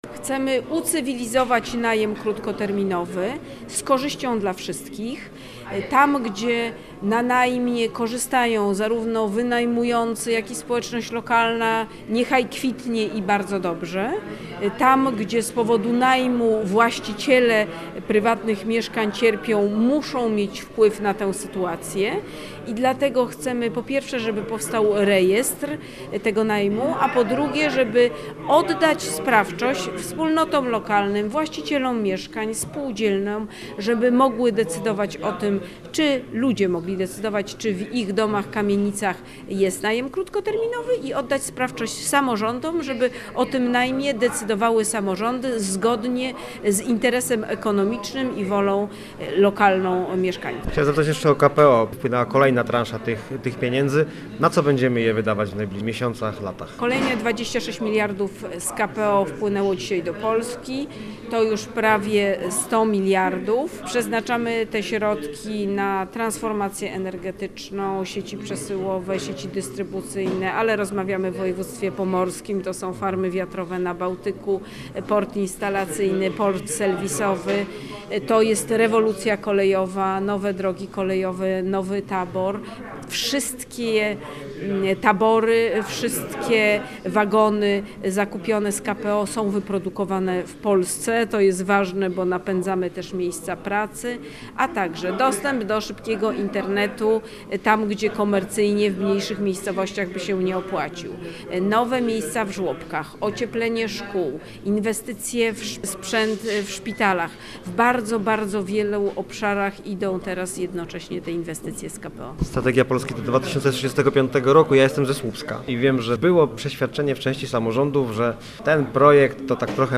Między innymi ten temat poruszyliśmy w rozmowie z Katarzyną Pełczyńską-Nałęcz, minister funduszy i polityki regionalnej. Mówiliśmy też o strategii rozwoju Polski do roku 2035 i awansie Słupska do kategorii regionalnej.